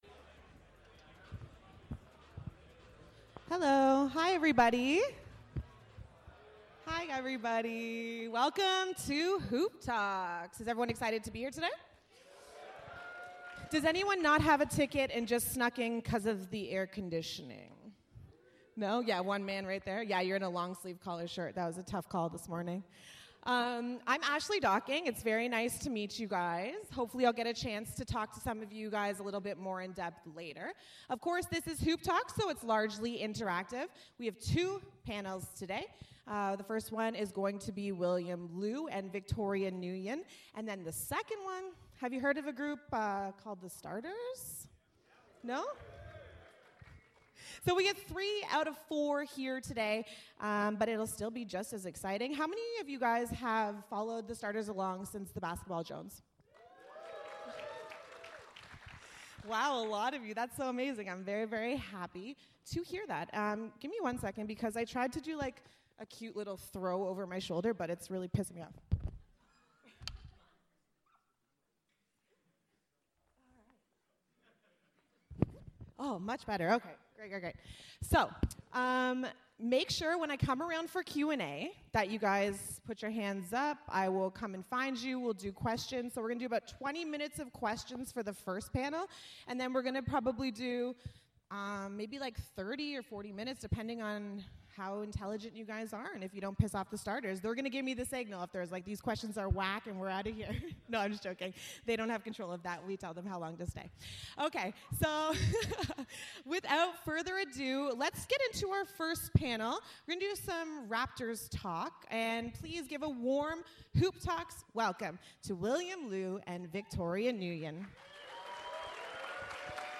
The Score panel at the June 30 edition of Hoop Talks.